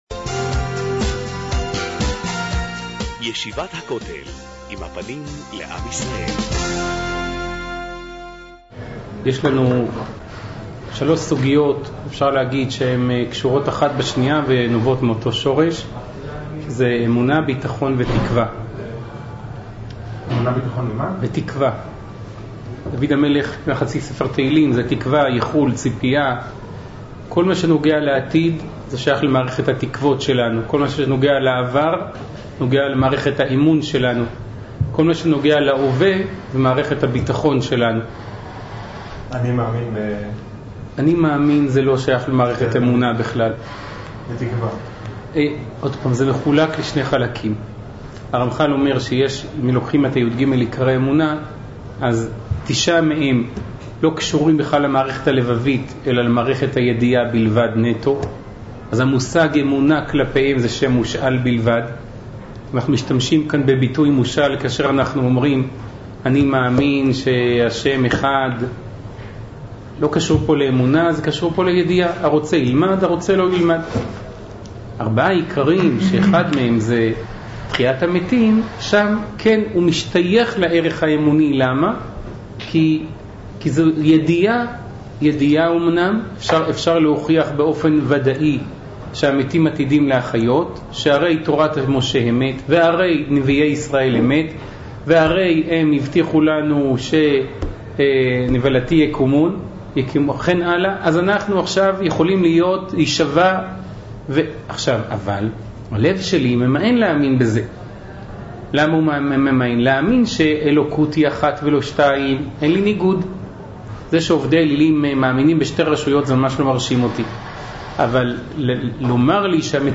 סיון תשע"ח להאזנה לשיעור